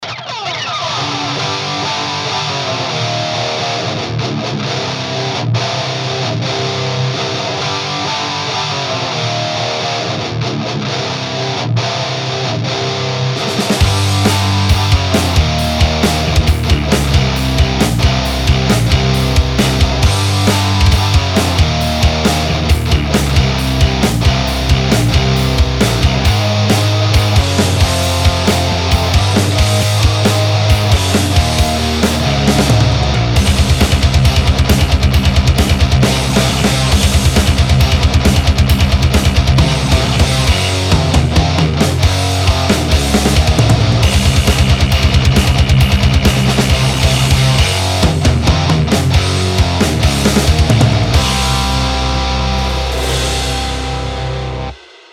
������ Scecter c-7